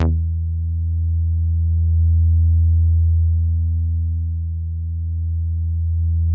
WAVEBASS  E3.wav